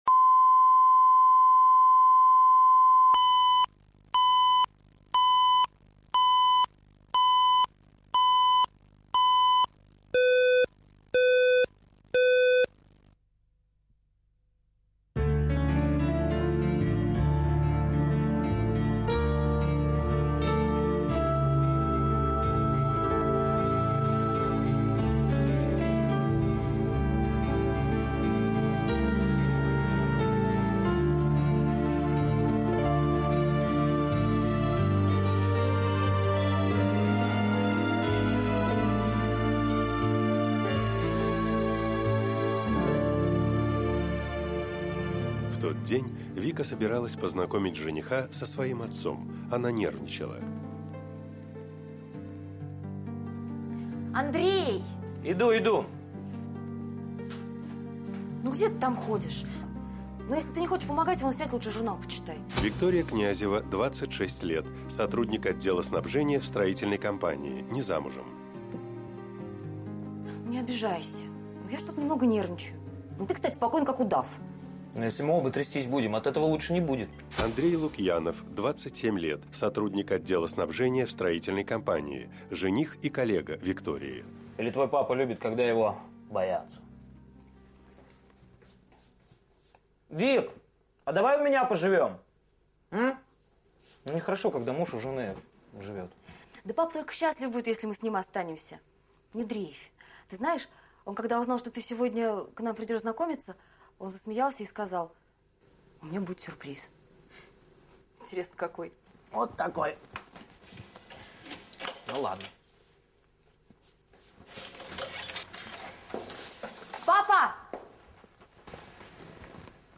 Аудиокнига Мой папа | Библиотека аудиокниг